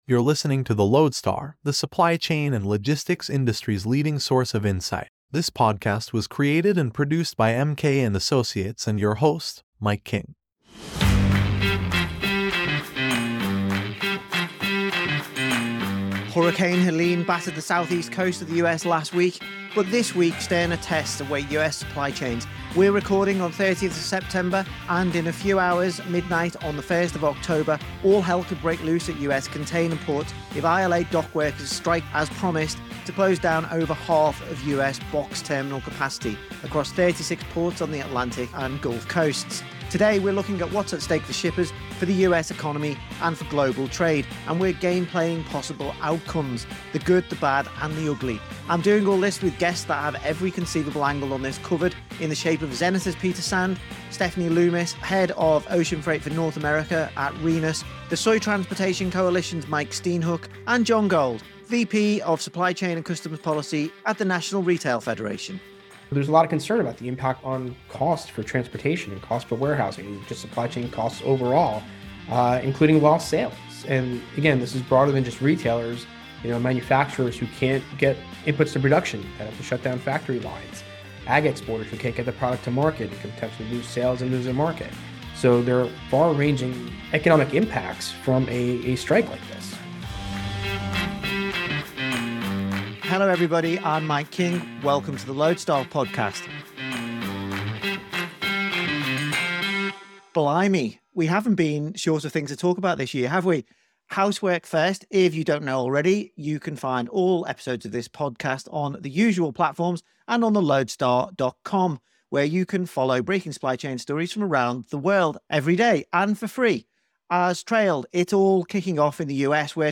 In this episode, recorded just hours before the strike action was due to begin, we break down the supply chain chaos that has built through September, explor